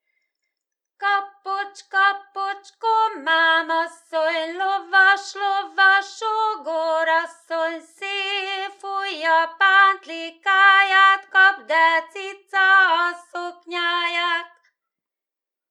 TípusI. Népi játékok / 05. Ügyességi és erőjátékok
TelepülésNagyfödémes [Veľké Úľany]